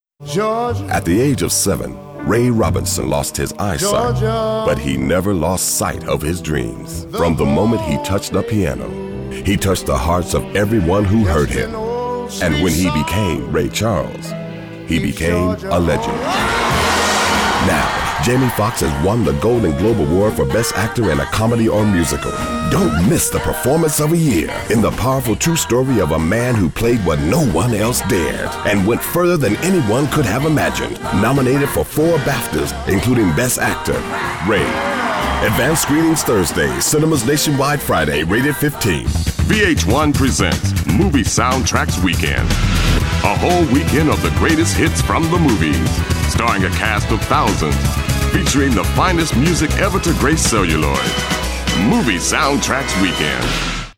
Movie Showreel
Promo, Cool, Smooth, Confident, Upbeat